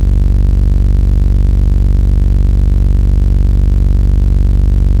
spaceEngine_001.ogg